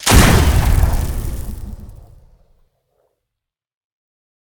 pistol1.ogg